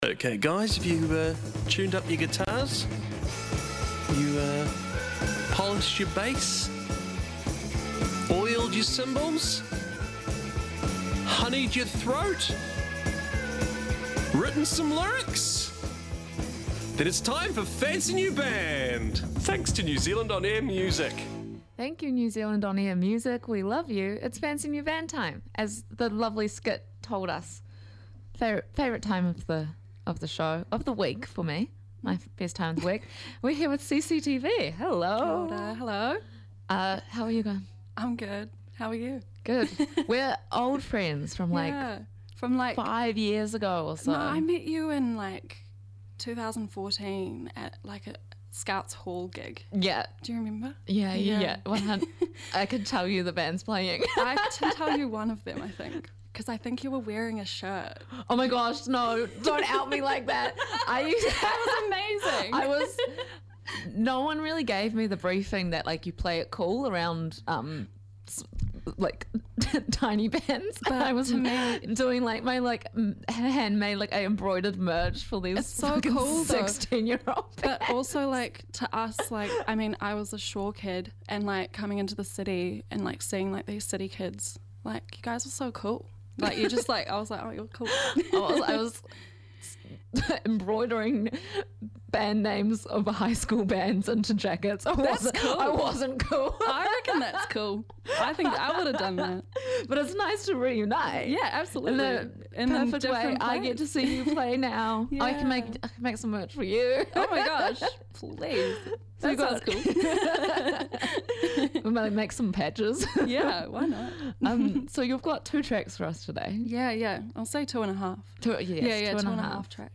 Riot Grrrl
shred in the studio